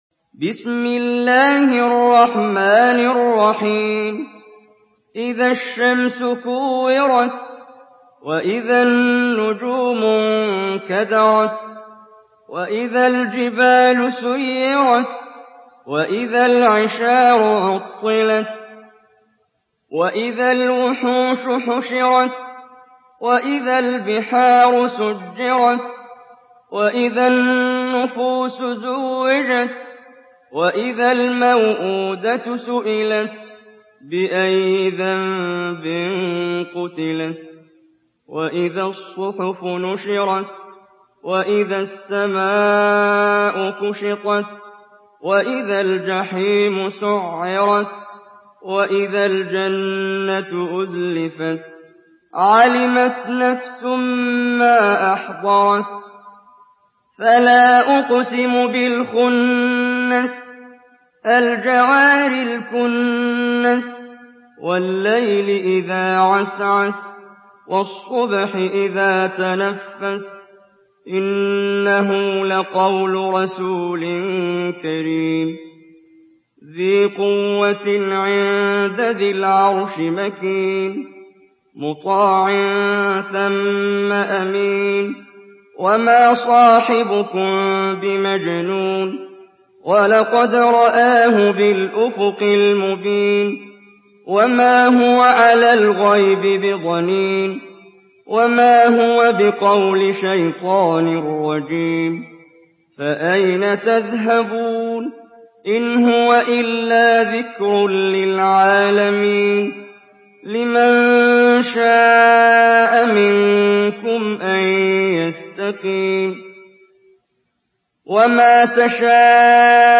سورة التكوير MP3 بصوت محمد جبريل برواية حفص
مرتل